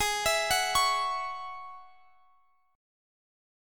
Listen to G#7sus4#5 strummed